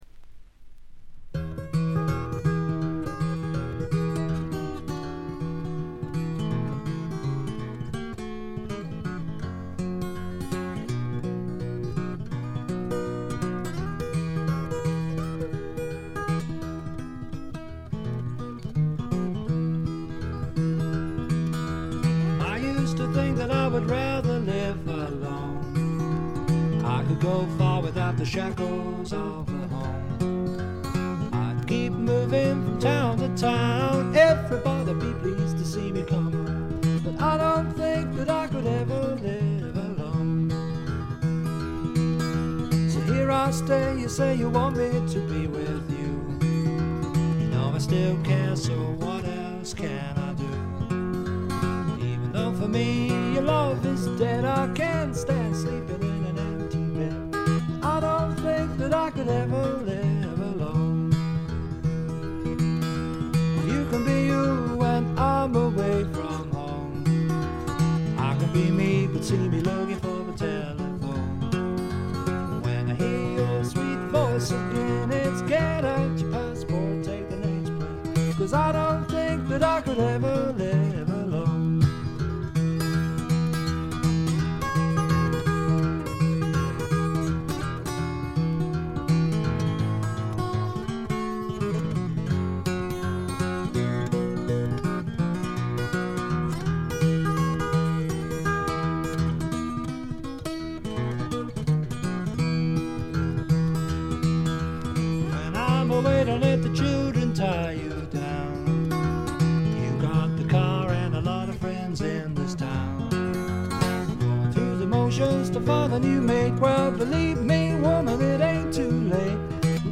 ところどころでチリプチ（特にB2は目立ちます）。鑑賞を妨げるようなノイズはありません。
試聴曲は現品からの取り込み音源です。